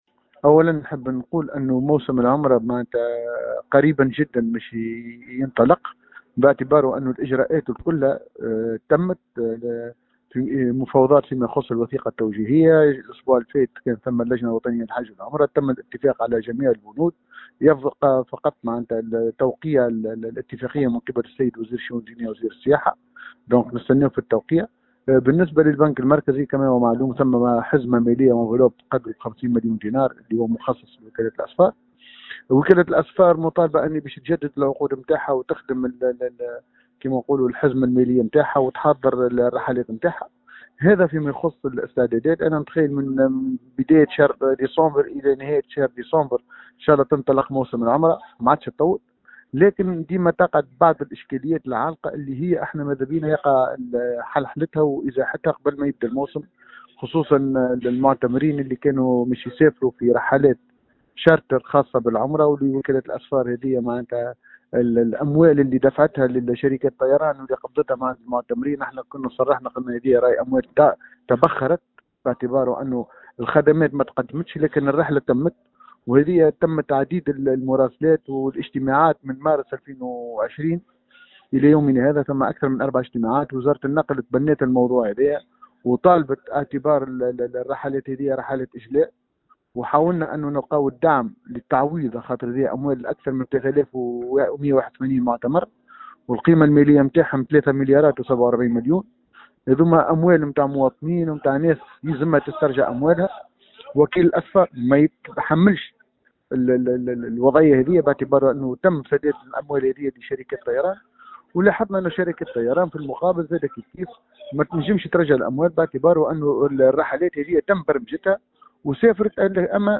ورجحّ في تصريح للجوهرة أف أم، انطلاق موسم العمرة خلال شهر ديسمبر القادم، داعيا إلى ضرورة فضّ الإشكال المتعلق بمستحقات المعتمرين الذين دفعوا معاليمهم كاملة سنة 2019، ولم يتمتعوا بخدمات العمرة جرّاء كوفيد-19 بالرغم من انطلاق الرحلات الجوية، مشيرا إلى أنّ هذه الأموال تبخرت وتمّ عقد أكثر من 4 اجتماعات للغرض..